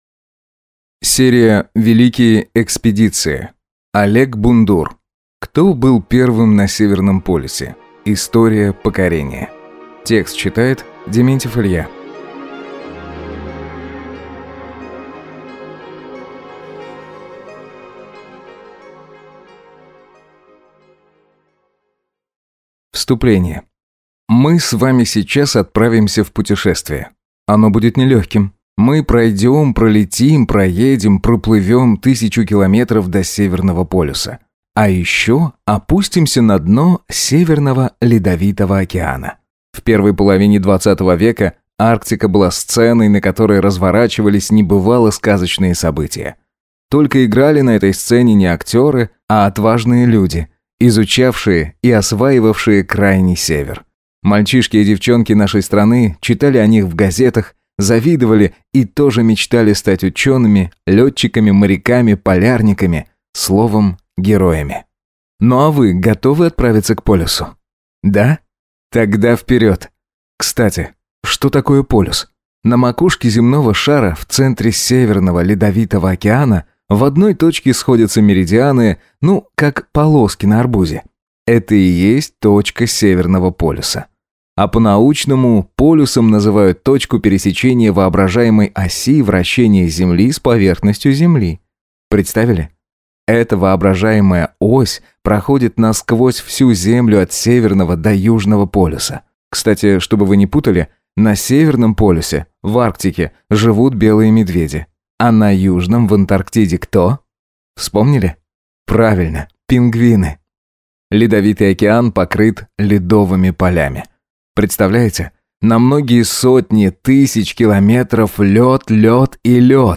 Аудиокнига Кто был первым на Северном полюсе | Библиотека аудиокниг